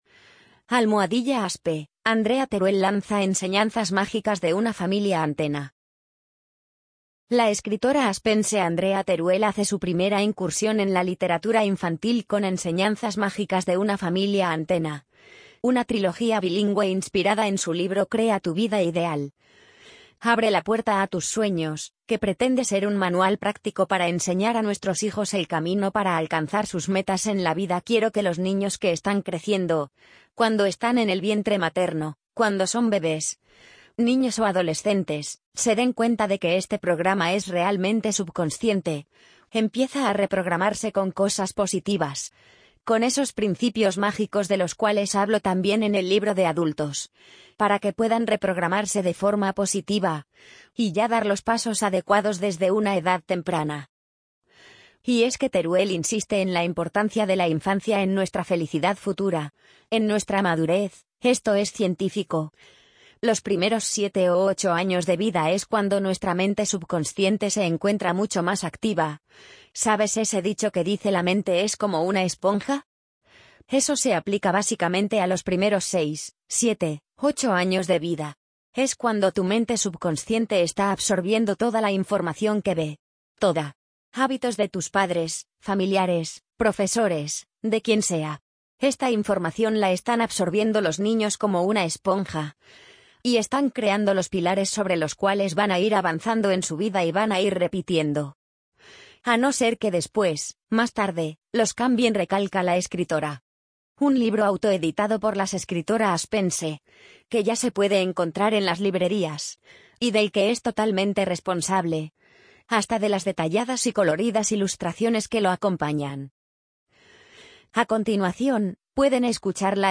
amazon_polly_71168.mp3